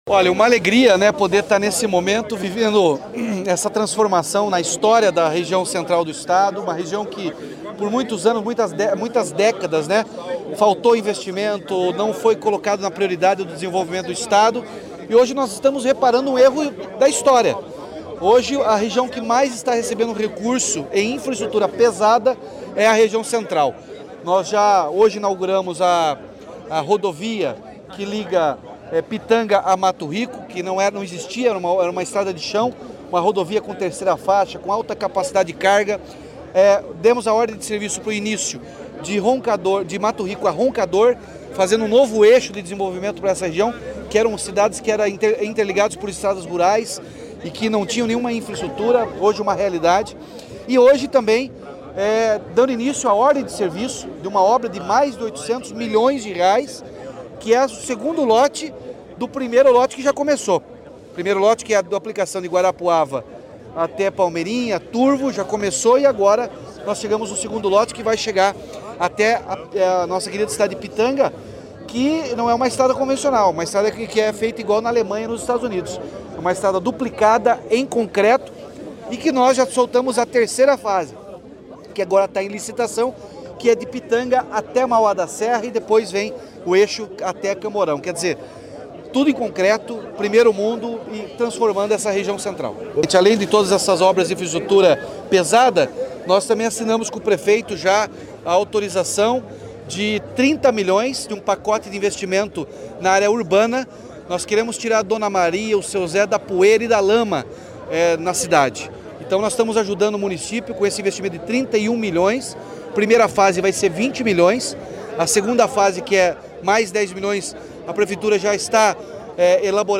Sonora do governador Ratinho Junior sobre a duplicação integral em concreto entre Guarapuava e Pitanga